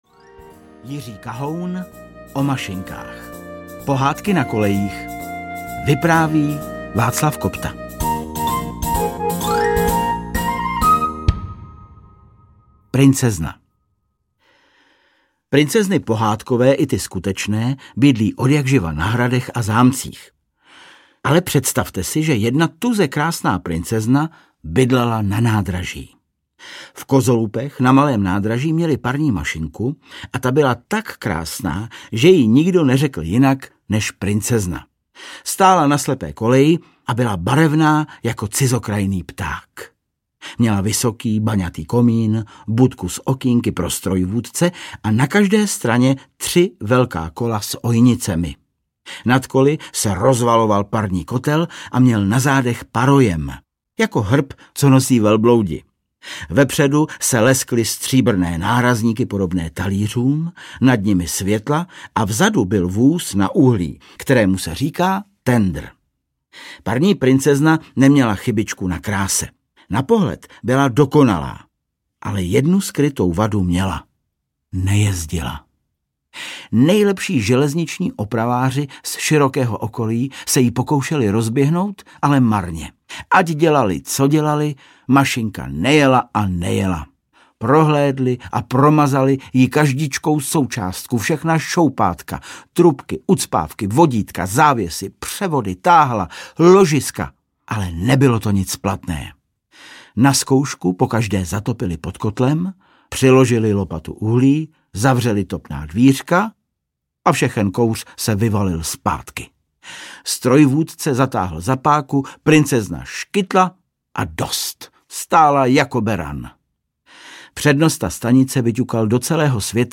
O mašinkách - Pohádky na kolejích audiokniha
Ukázka z knihy